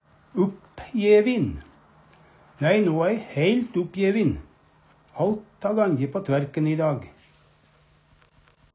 uppjevin/uppjedd - Numedalsmål (en-US)
Høyr på uttala Ordklasse: Adjektiv Attende til søk